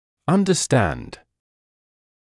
[ˌʌndə’stænd][ˌандэ’стэнд]понимать; осмысливать